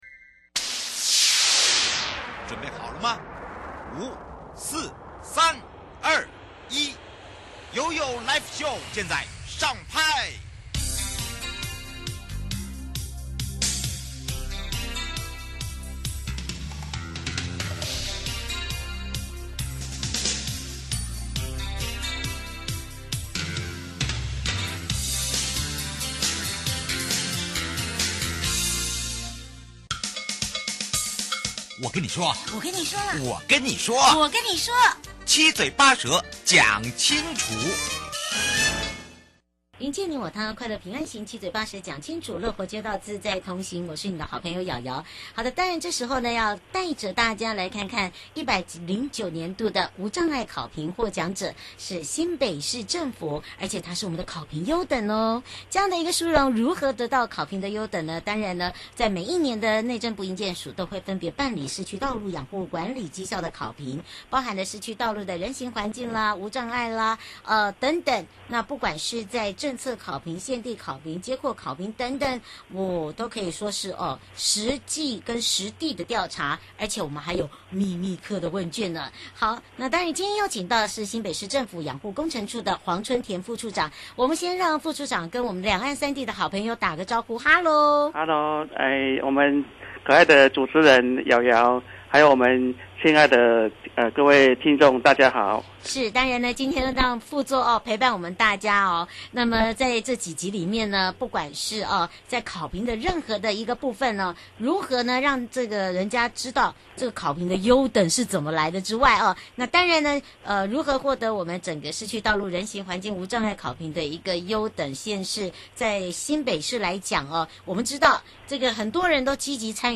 受訪者： 109 年度無障礙考評獲獎者-新北市政府-考評優等 (上集) 針對新北市有哪一些具體的政策及如何推動